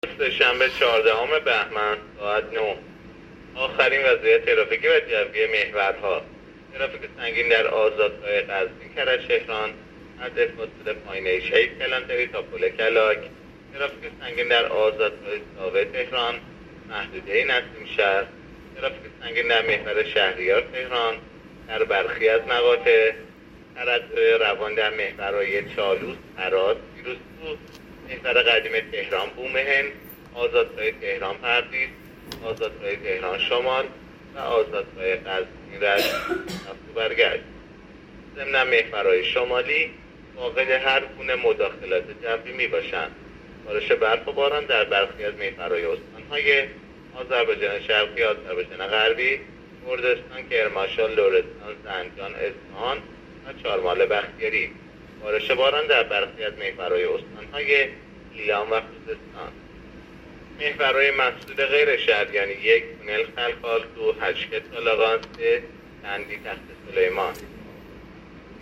گزارش رادیو اینترنتی از آخرین وضعیت ترافیکی جاده‌ها ساعت ۹ چهاردهم بهمن؛